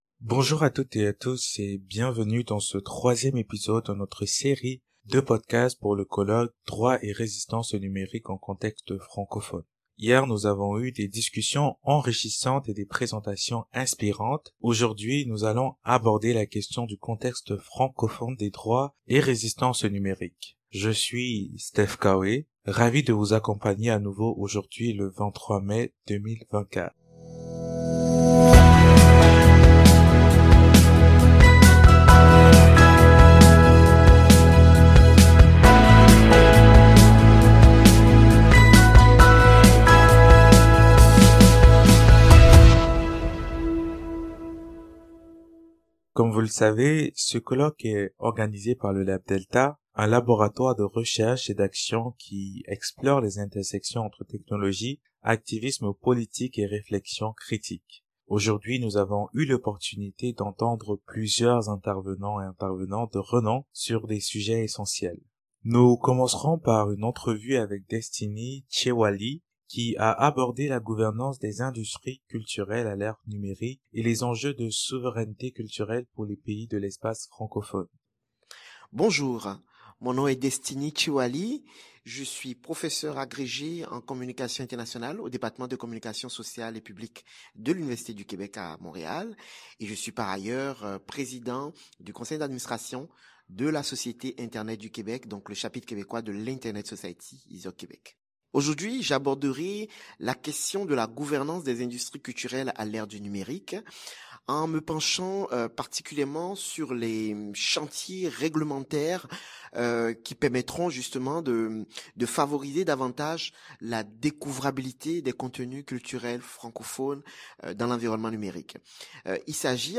Entrevues avec des présentateur·trice·s et des professeur·e·s invité·e·s.